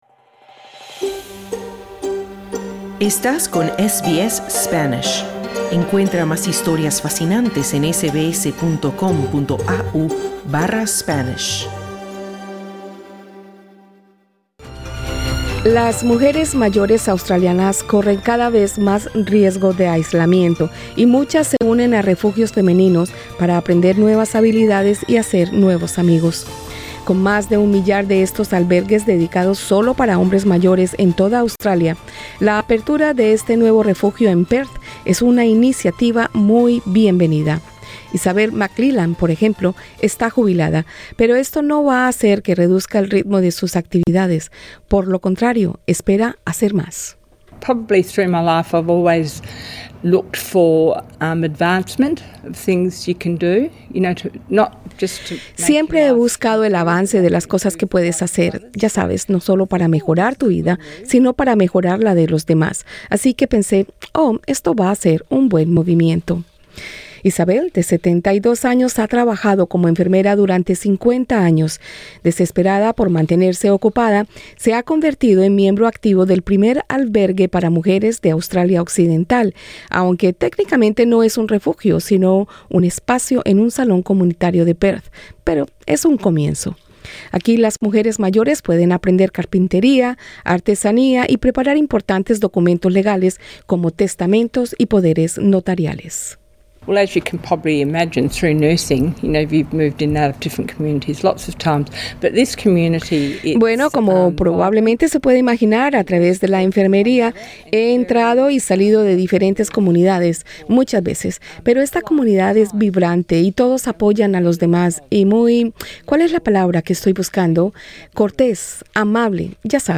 Con una población de tercera edad cada vez mayor en Australia, las mujeres buscan centros de socialización para evitar el aislamiento. Entrevista